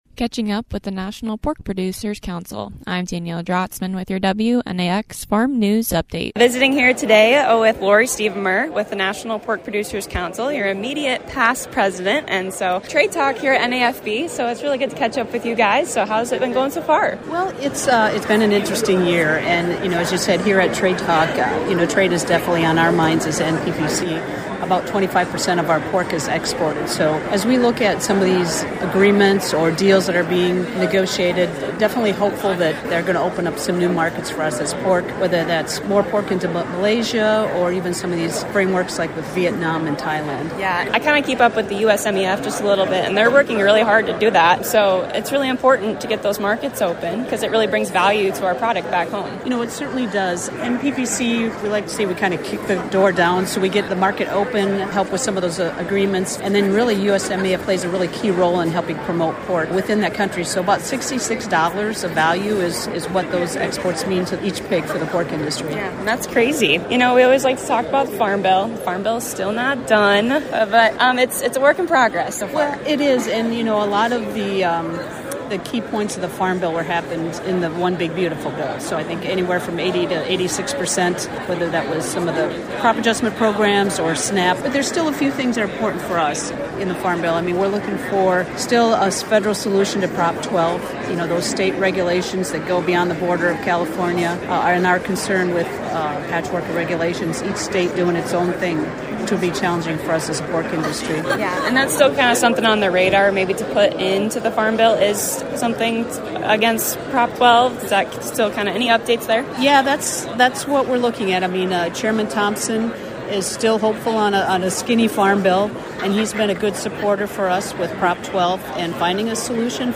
Catching up with the National Pork Producers Council at the National Association Farm Broadcasting Convention.